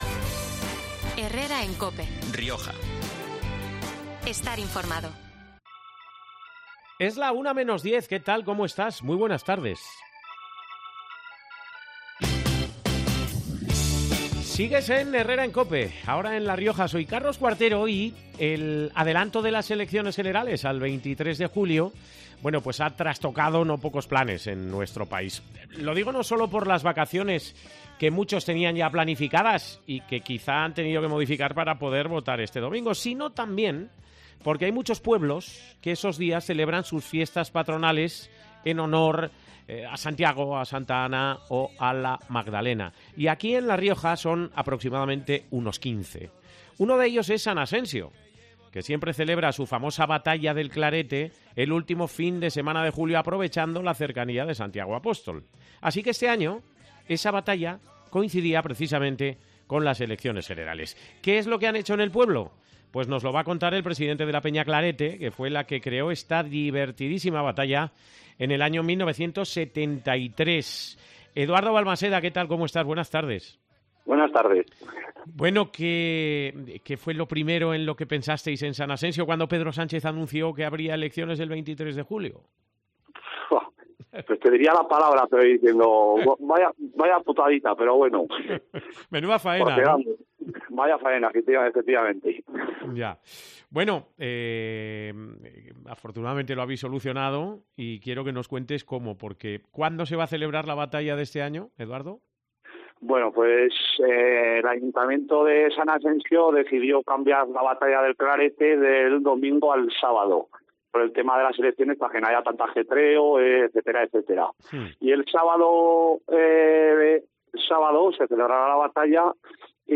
ha pasado este 17 de julio por los micrófonos de COPE Rioja para hablarnos de esta Fiesta de Interés Turístico Regional desde el año 2012